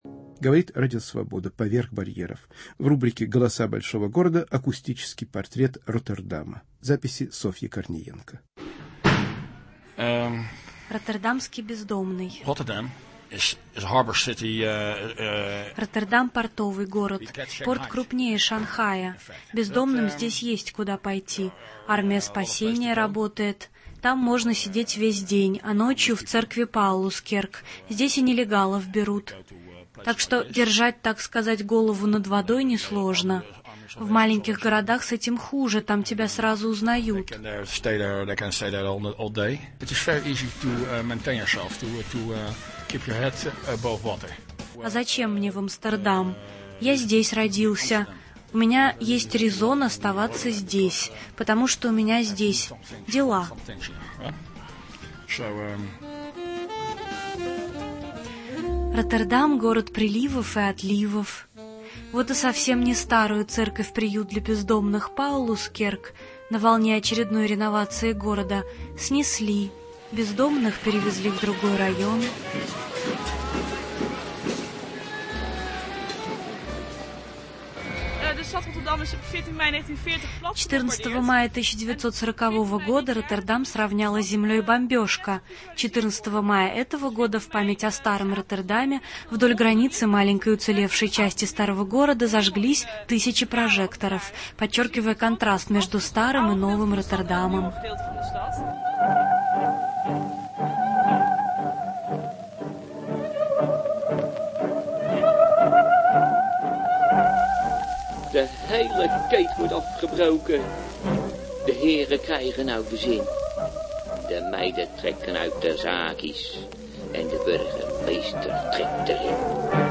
"Голоса Роттердама". Акустический портрет крупнейшего европейского порта